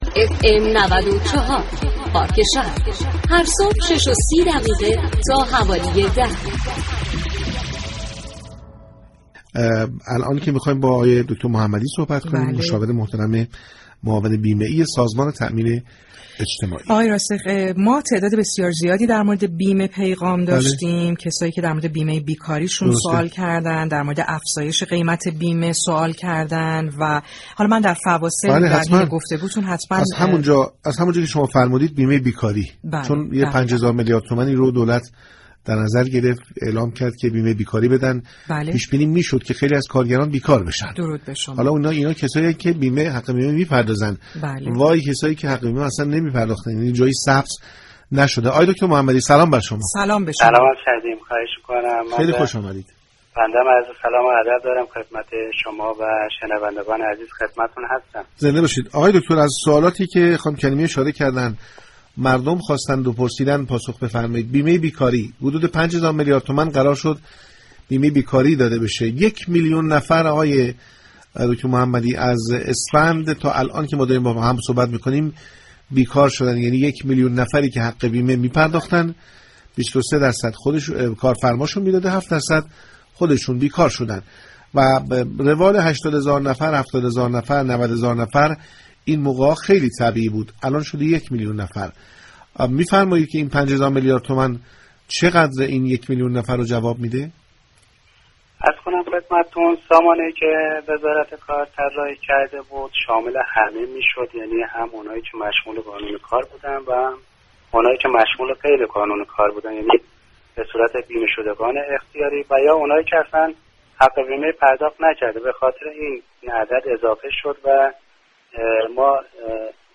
در گفتگو با پارك شهر